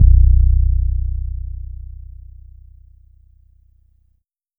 BASS 1.wav